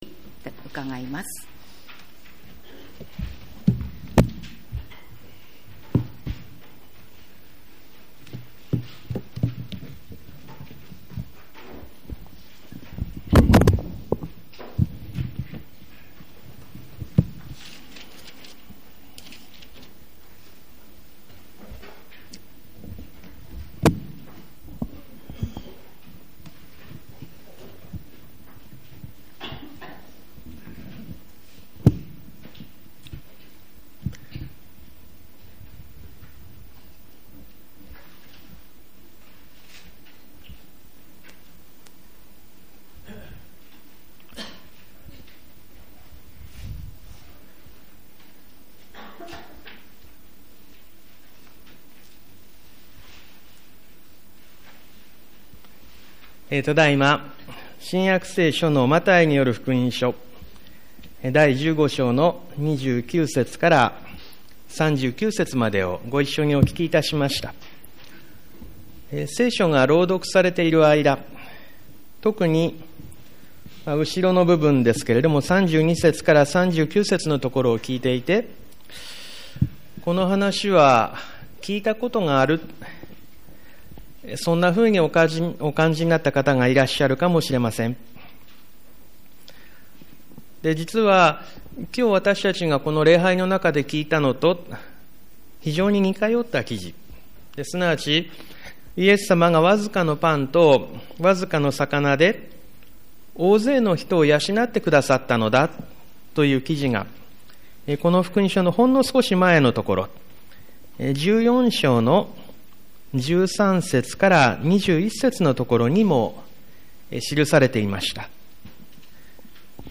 ■ ■ ■ ■ ■ ■ ■ ■ ■ 2017年11月 11月5日 11月12日 11月19日 11月26日 毎週日曜日の礼拝で語られる説教（聖書の説き明かし）の要旨をUPしています。